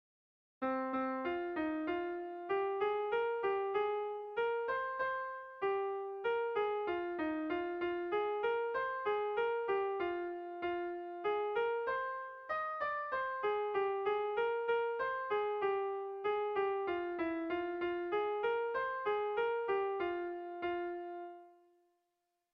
Air de bertsos - Voir fiche   Pour savoir plus sur cette section
Erlijiozkoa
Zortziko txikia (hg) / Lau puntuko txikia (ip)
ABDB